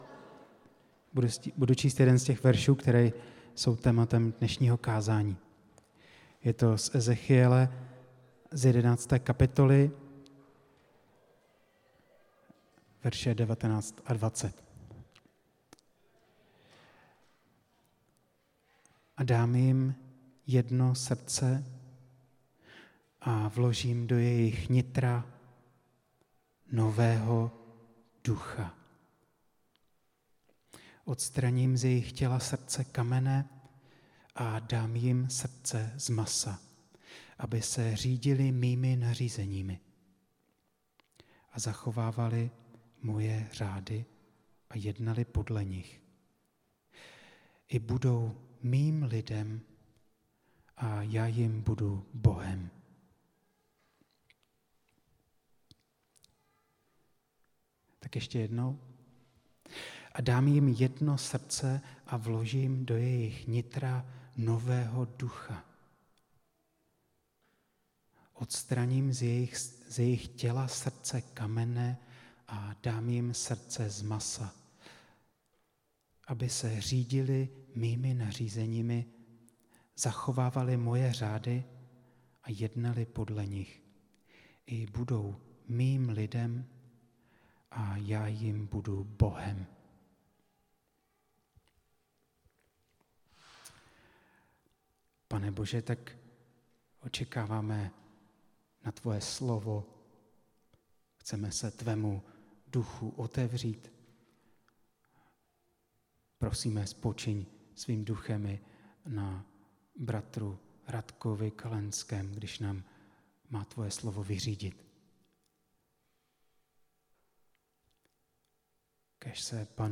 Událost: Kázání
Místo: Římská 43, Praha 2